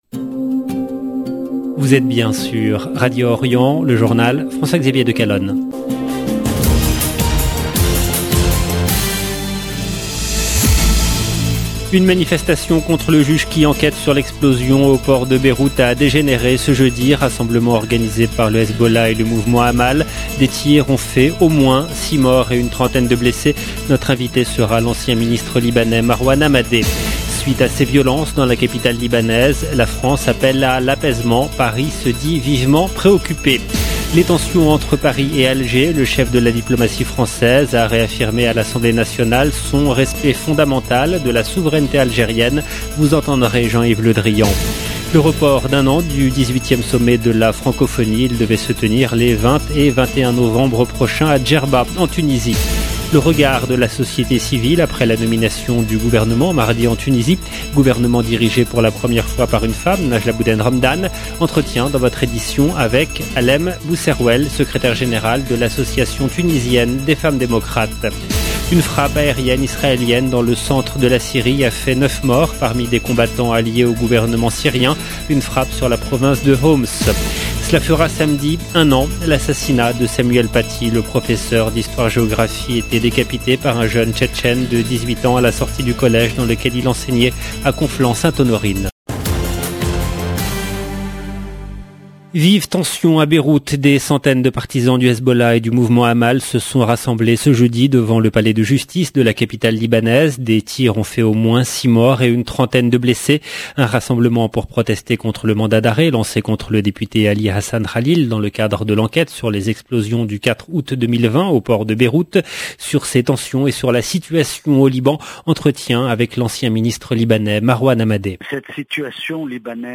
Le journal du soir en langue française
Vous entendrez Jean-Yves Le drian. Le report d’un an du 18e sommet de la Francophonie.